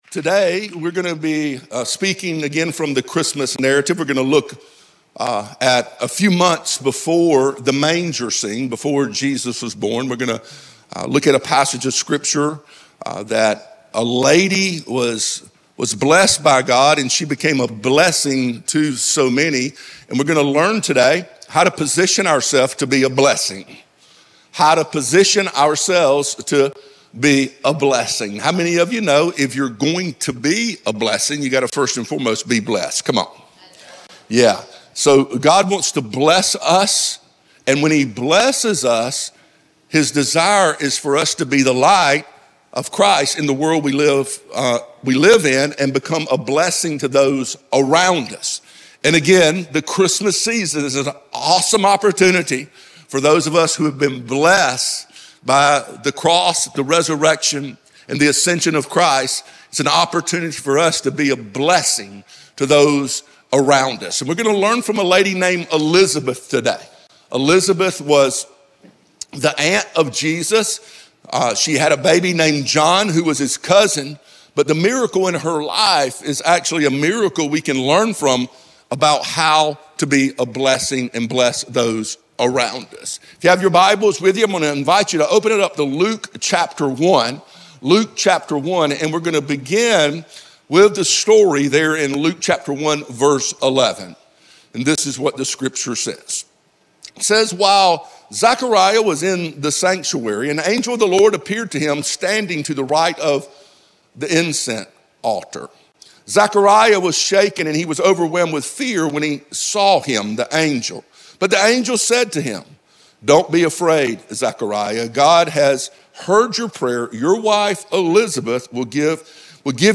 a dynamic, high-energy speaker